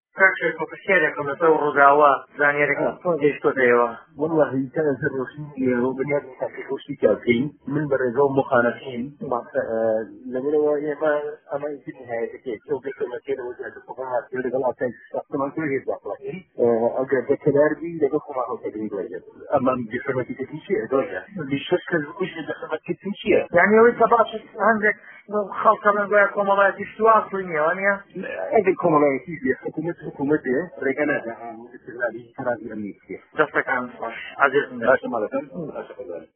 ده‌قی گفتوگۆكه‌ی